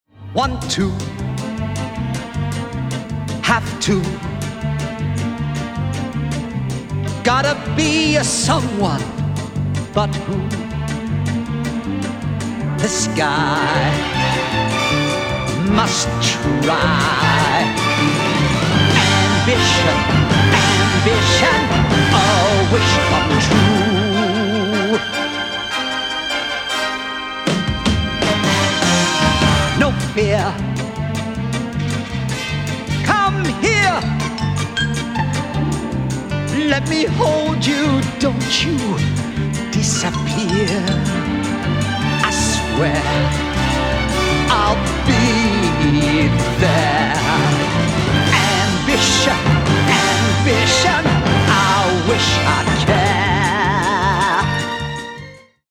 Lavishly orchestrated